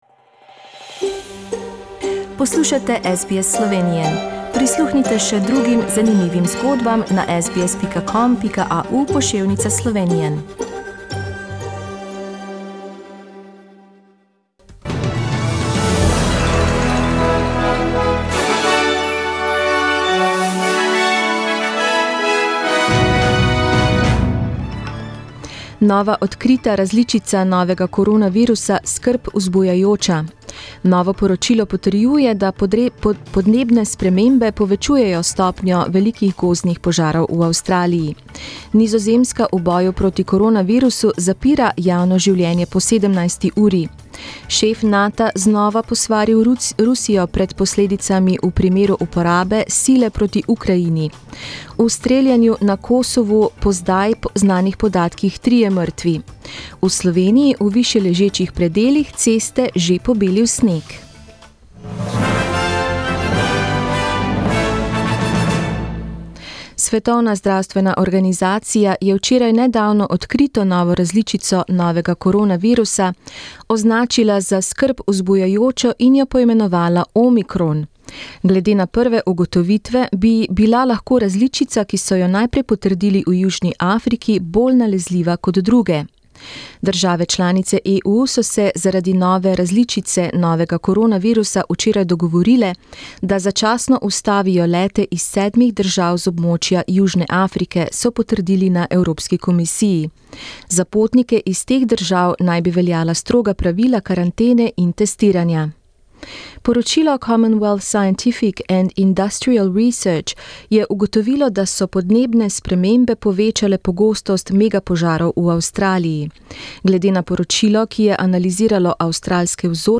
Poročila v slovenskem jeziku 27.novembra